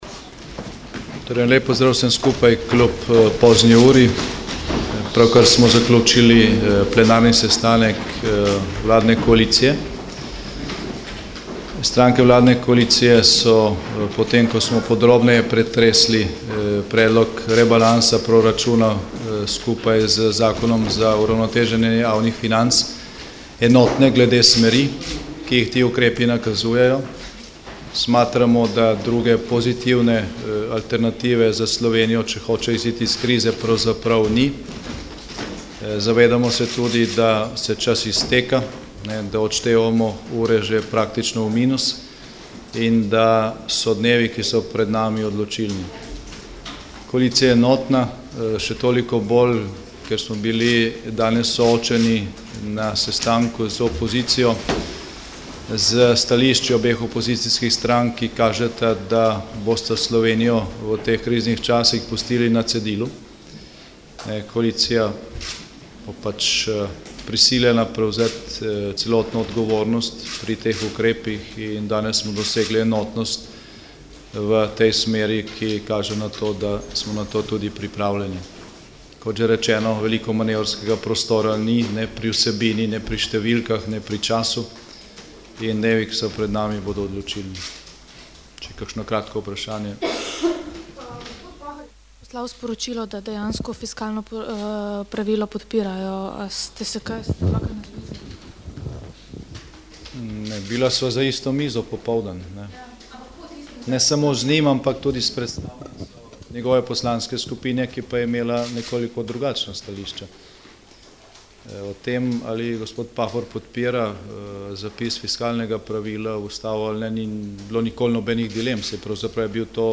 Predsednik slovenske vlade Janez Janša je v izjavi za medije še dejal, da je koalicija enotna tudi o obsegu in smeri predloga ukrepov.